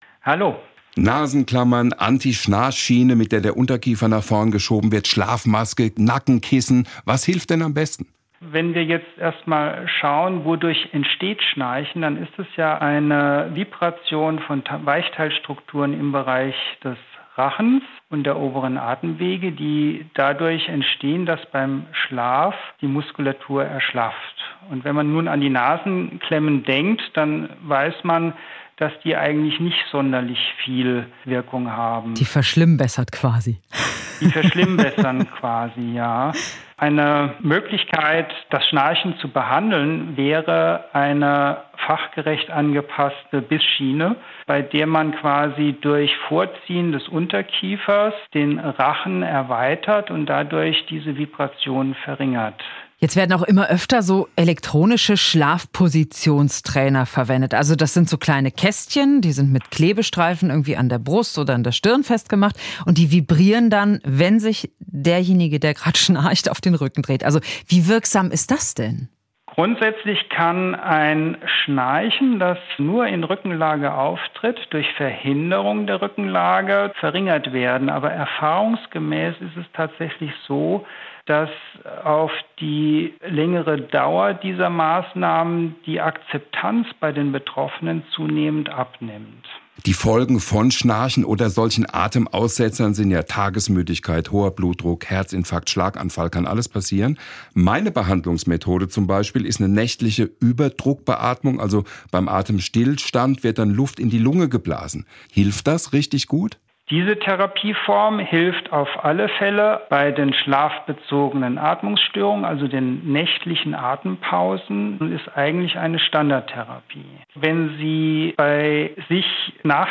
Mehr Interviews